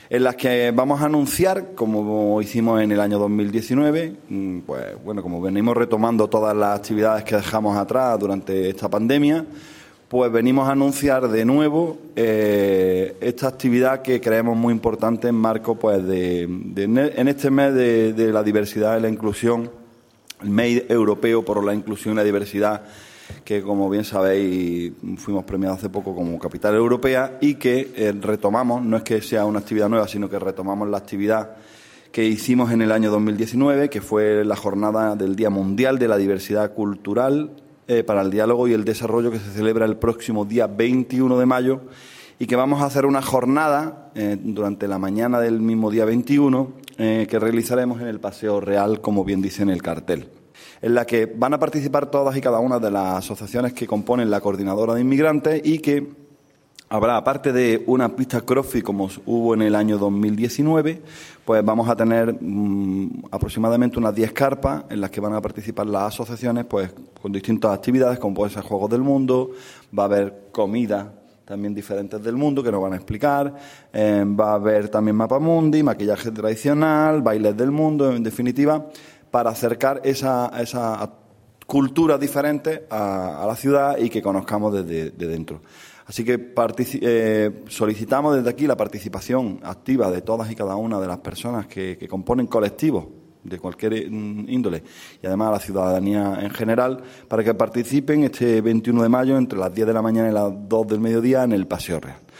El teniente de alcalde delegado de Programas Sociales, Alberto Arana, ha confirmado hoy en rueda de prensa que este sábado 21 de mayo se retomará la jornada conmemorativa del Día Mundial de la Diversidad Cultural para el Diálogo y el Desarrollo, iniciativa enmarcada dentro del Mes Europeo por la Inclusión y la Diversidad que ya se puso en marcha en el año 2019 y que, a consecuencia de la pandemia, no ha podido celebrarse durante los dos últimos años.
Cortes de voz